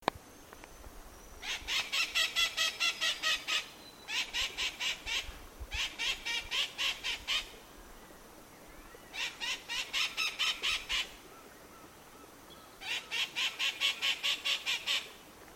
Periquitão-maracanã (Psittacara leucophthalmus)
Nome em Inglês: White-eyed Parakeet
Fase da vida: Adulto
Localidade ou área protegida: Parque Provincial Cruce Caballero
Condição: Selvagem
Certeza: Observado, Gravado Vocal